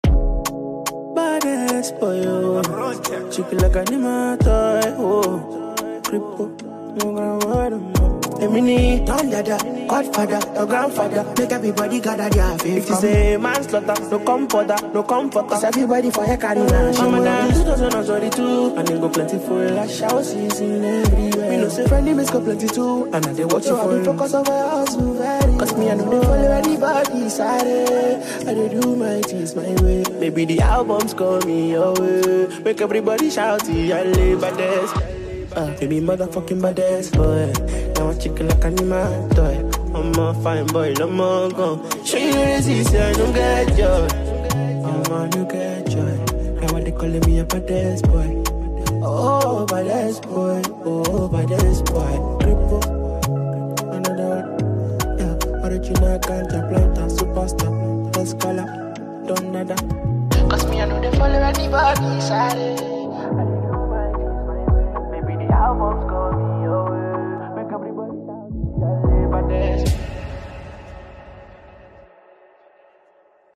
Sensational Nigerian singer